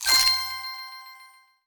Special & Powerup (11).wav